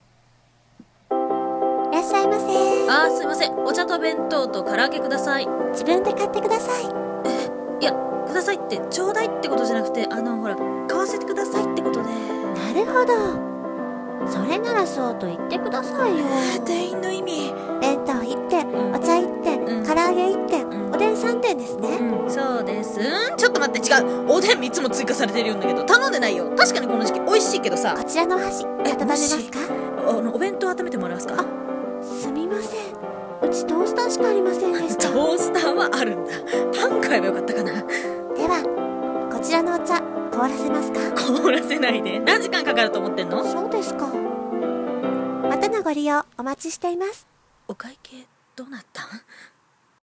【二人声劇台本】コンビニ【ギャグ声劇】